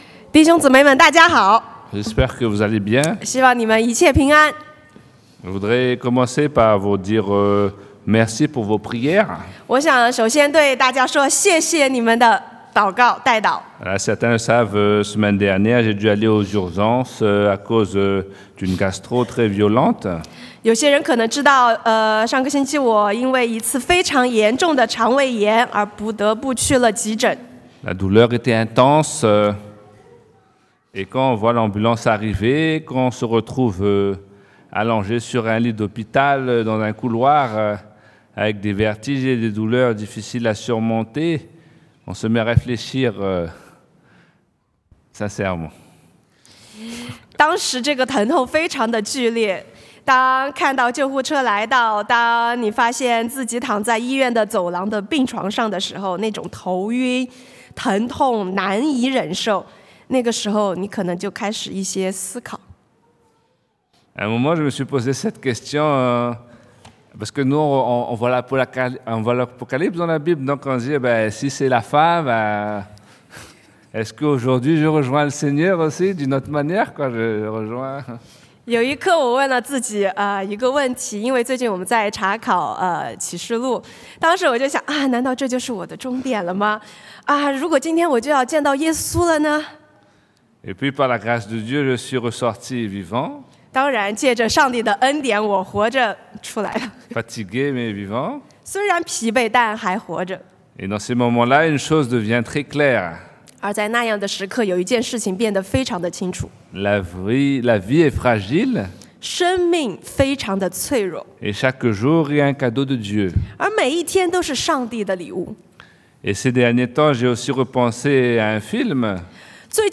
Passage: Josué 约书亚记 2 : 15-24 Type De Service: Predication du dimanche